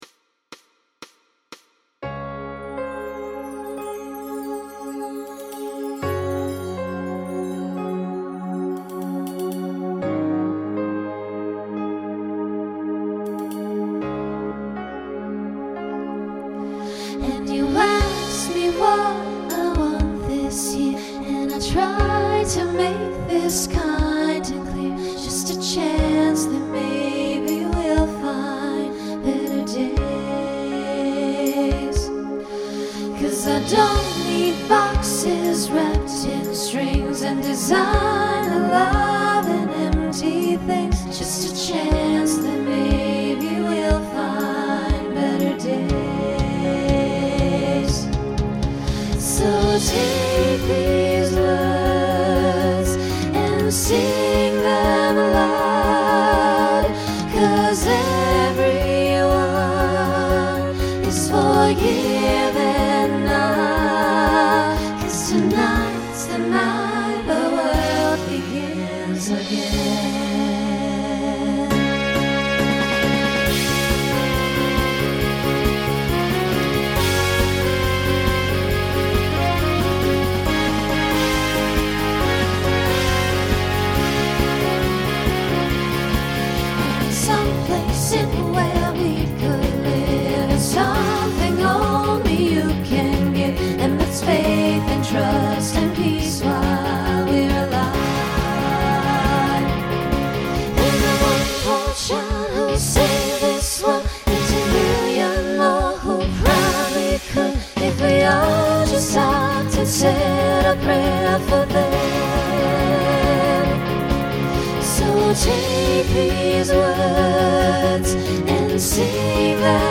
Voicing SATB Instrumental combo Genre Rock